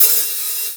059 - HH-3O.wav